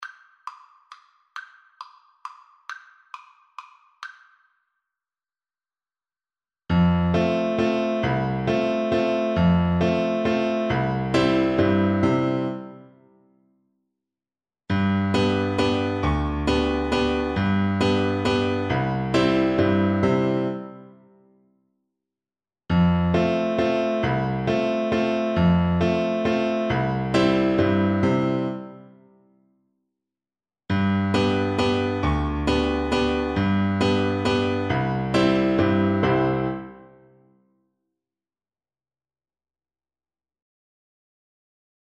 C major (Sounding Pitch) (View more C major Music for Tuba )
3/4 (View more 3/4 Music)
One in a bar .=c.45
C3-C4
Traditional (View more Traditional Tuba Music)